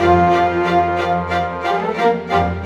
Rock-Pop 07 Orchestra 02.wav